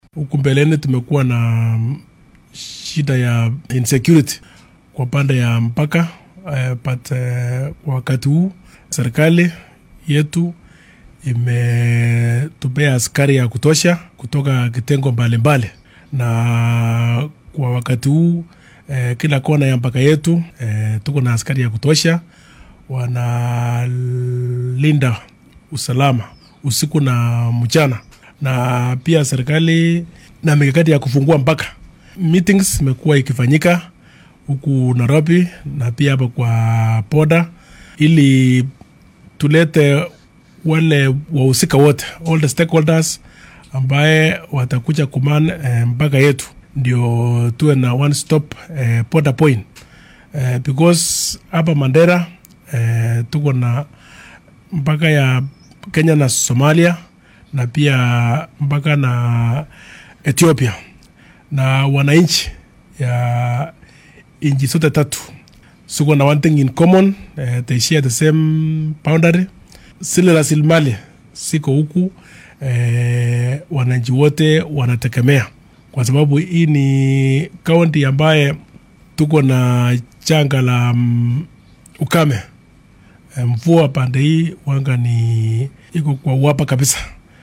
Laamaha ammaanka iyo dhinacyada kala duwan ee si gaar ah u daneeya nabadgalyada ee ismaamulka Mandera ayaa qorsheynaya in dib loo furo xadka ay wadagaan waddamada dariska ee Kenya iyo Soomaaliya. Arrintan ayaa la doonaya in lagu fududeeyo ganacsiga labada dhinac iyo ka falcelinta saameynta abaarta. Mid ka mid ah saraakiisha ammaanka oo ka hadlay qorshaha lagu furayo xadka Kenya iyo Soomaaliya ayaa yiri